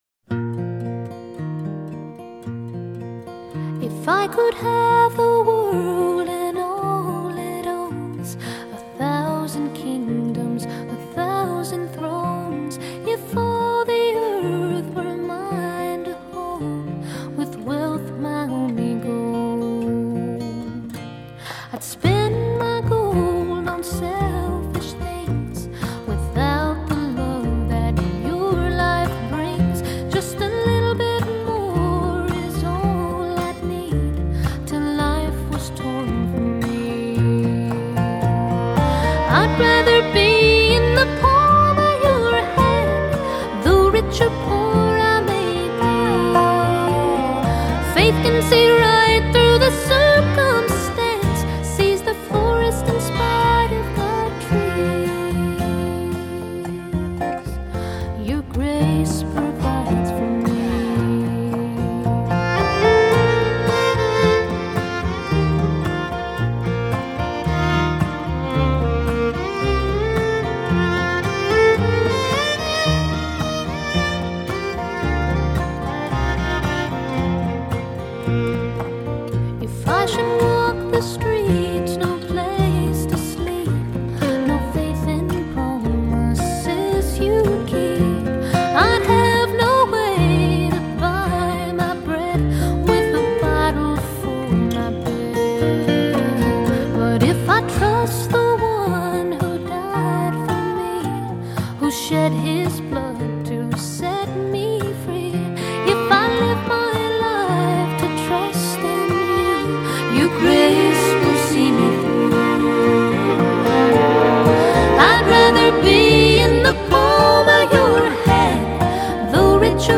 ★ 藍草音樂天后早期代表作，雙白金暢銷專輯！
★ 全音音樂網站五顆星無條件推薦，《滾石》雜誌四星高評，歌曲首首動聽，錄音鮮活絲滑！